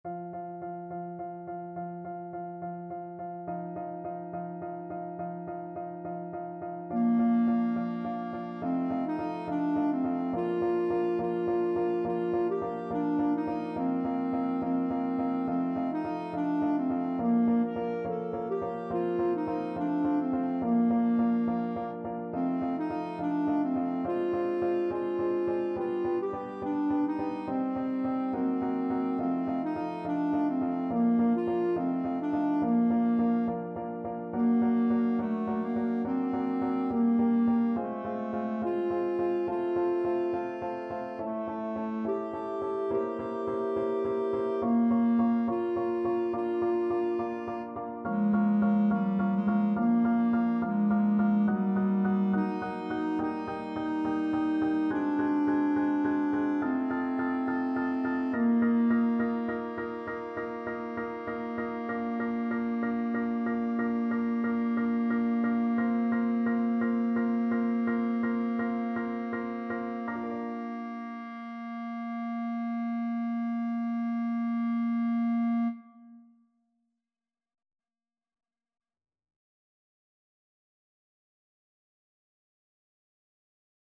Free Sheet music for Clarinet
Clarinet
4/4 (View more 4/4 Music)
Andante maestoso
Classical (View more Classical Clarinet Music)
saint_saens_the_tortoise_CL.mp3